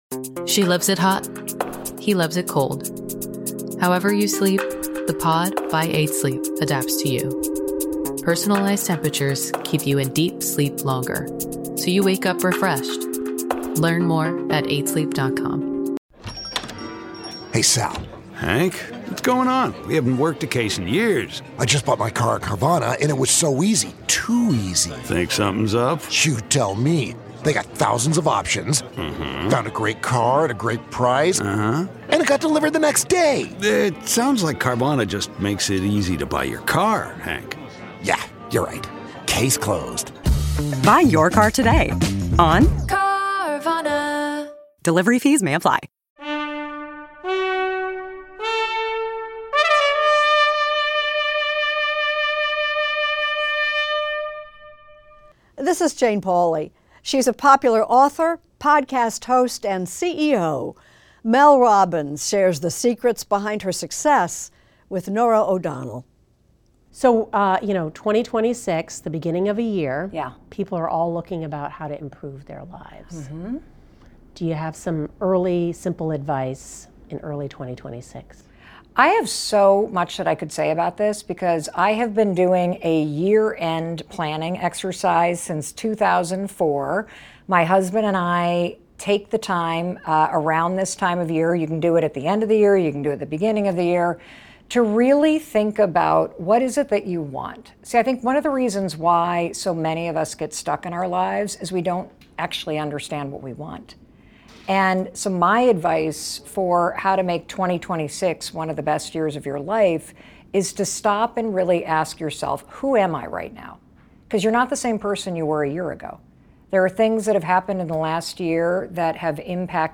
Extended Interview: Mel Robbins
Author and podcaster Mel Robbins talks with Norah O'Donnell about "The Let Them Theory."